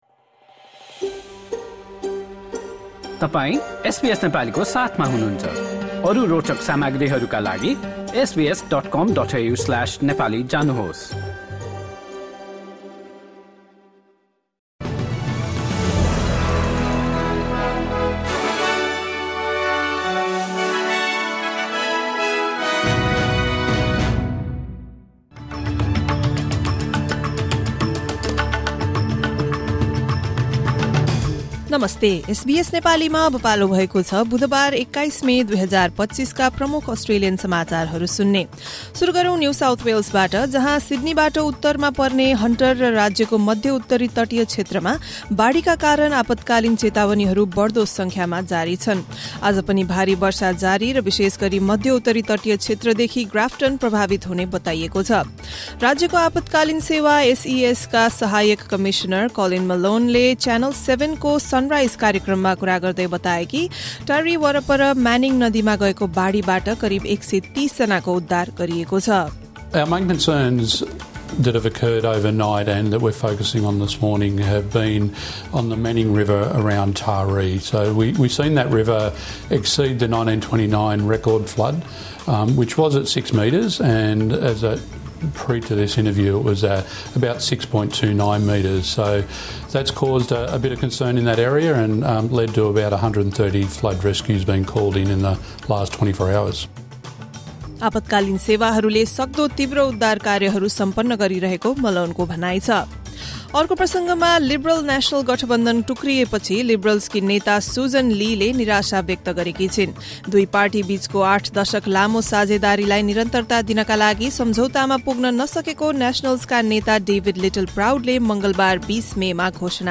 SBS Nepali Australian News Headlines: Wednesday, 21 May 2025